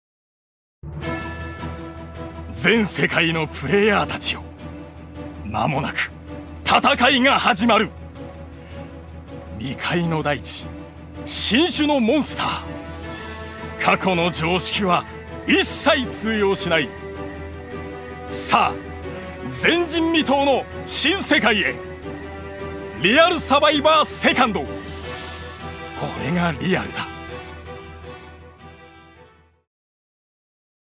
所属：男性タレント
ナレーション４